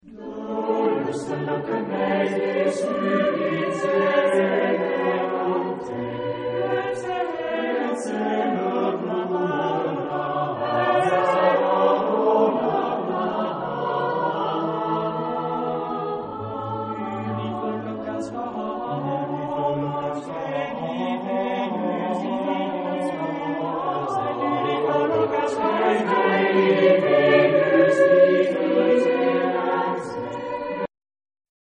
Epoque : 16ème s.
Genre-Style-Forme : Renaissance ; Profane
Type de choeur : SATB  (4 voix mixtes )
Tonalité : ré majeur